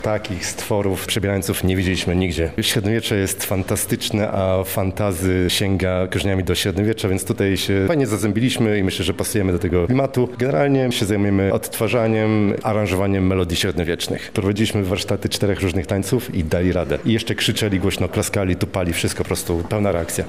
O emocjach towarzyszących swojemu występowi mówi zespół Dziwoludy: